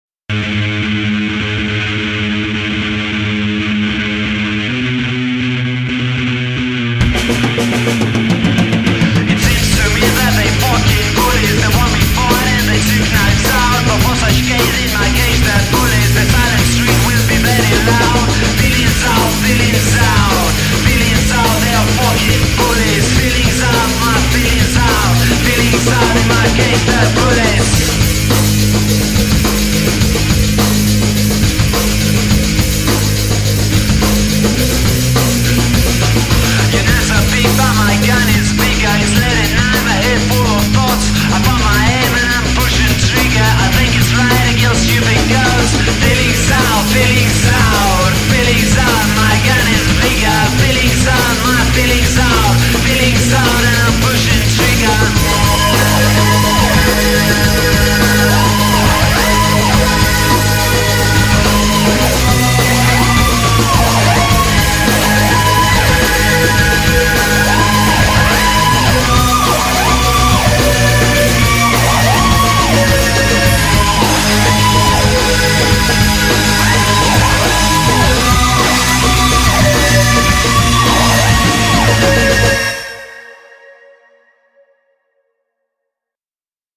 барабанщик